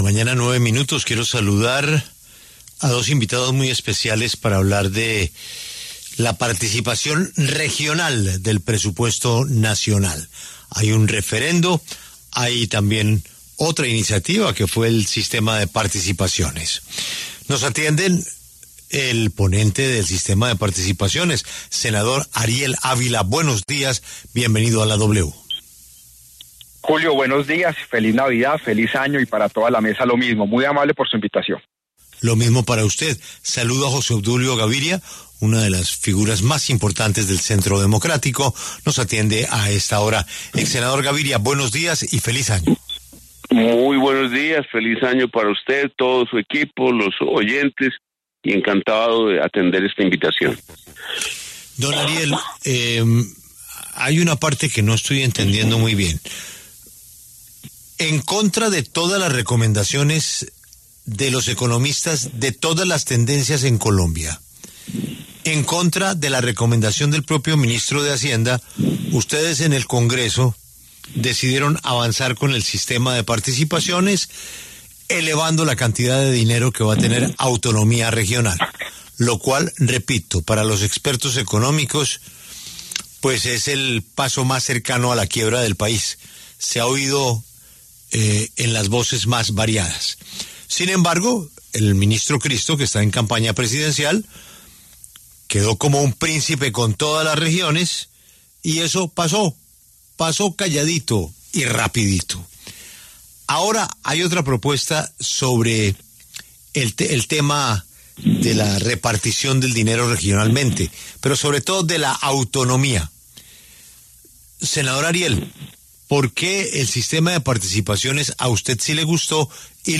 El senador Ariel Ávila y el exsenador José Obdulio Gaviria se refirieron en La W al referendo que busca la autonomía fiscal de las regiones de Colombia.